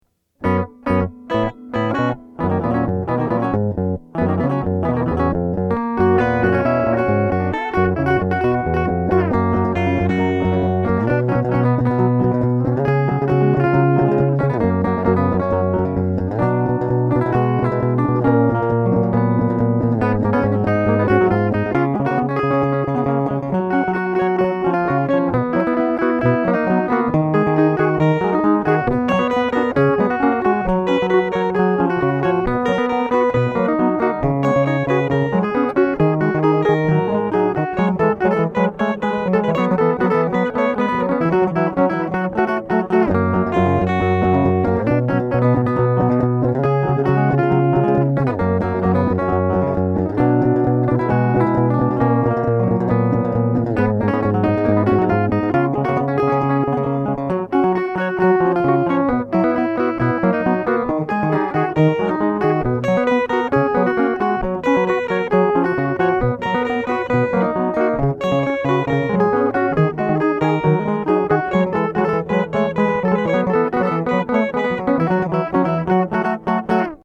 3 voices for guitar (pdf) (